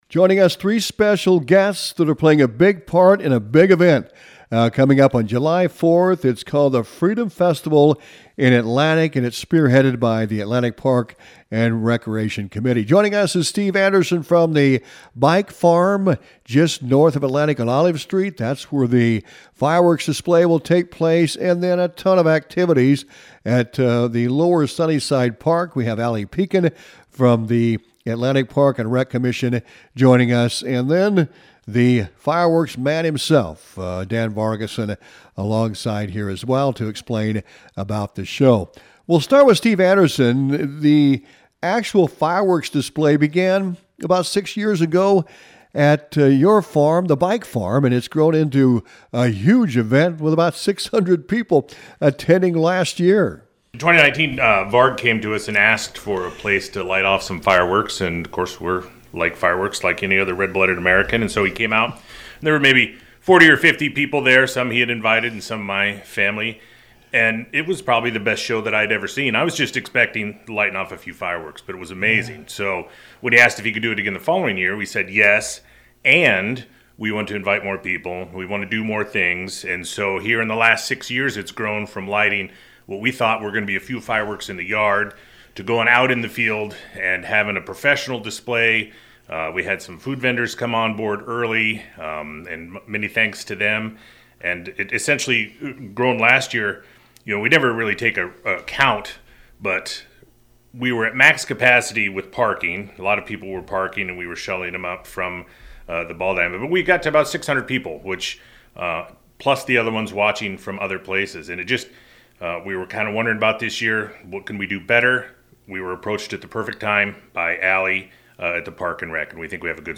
freedom-festival-interview-final.mp3